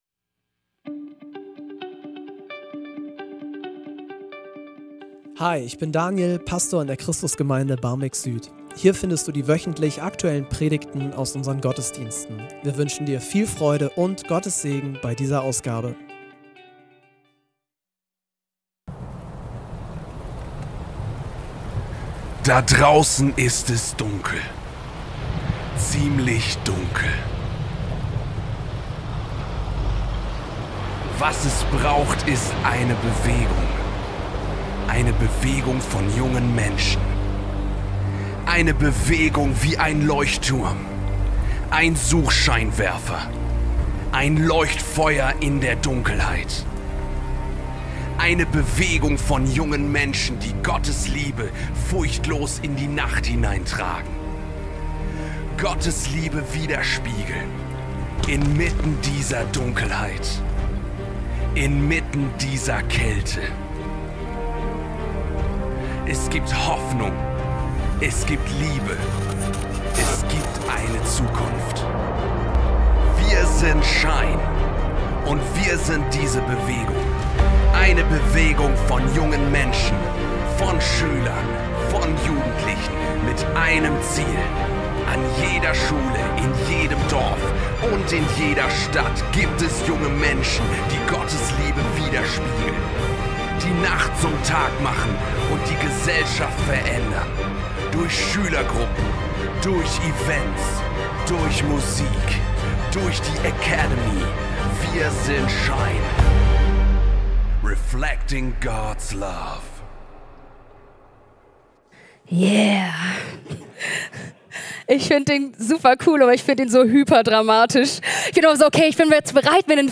Ihr seid das Licht der Welt - SHINE Gottesdienst ~ Christus Gemeinde Barmbek-Süd Podcast